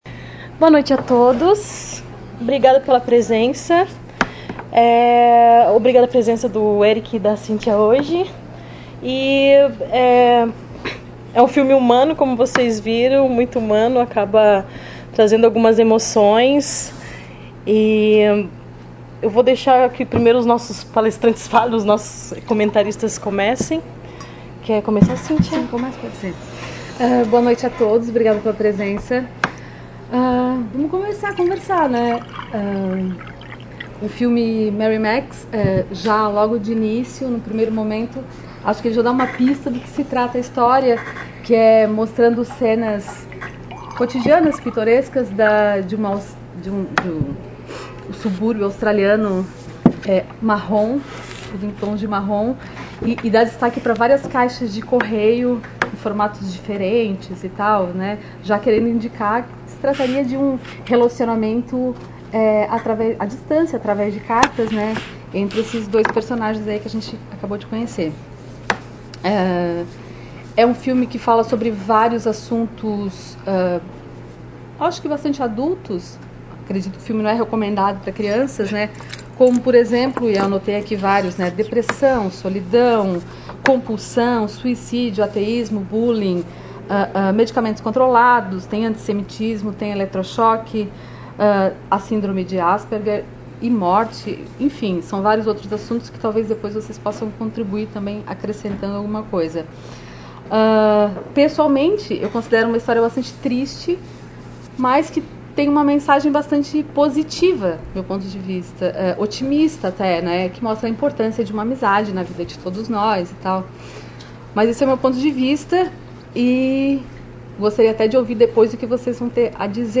Comentários dos debatedore(a)s convidado(a)s
realizada em 02 de maio de 2019 no Auditório "Elke Hering" da Biblioteca Central da UFSC.